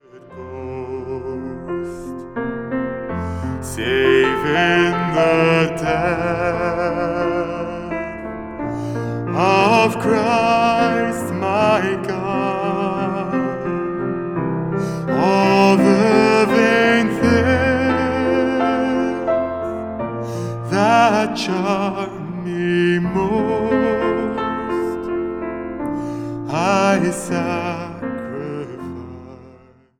Zang | Solozang